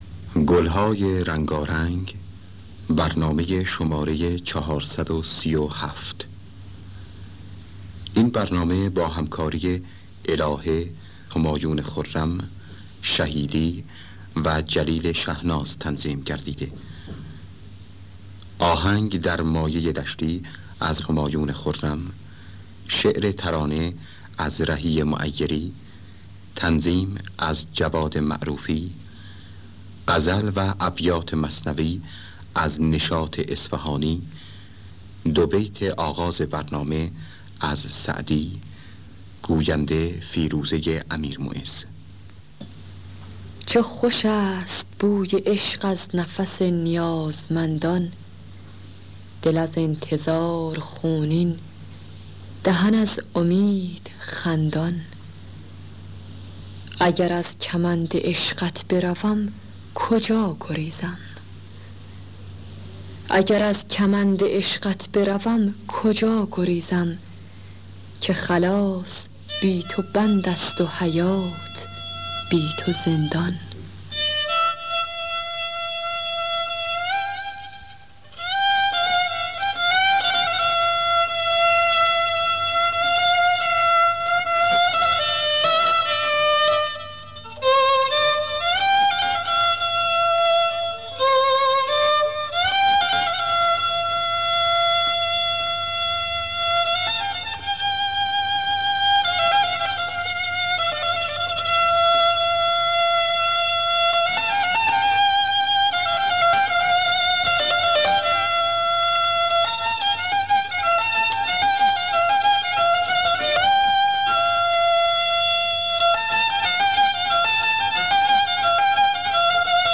خوانندگان: الهه عبدالوهاب شهیدی نوازندگان: جلیل شهناز جواد معروفی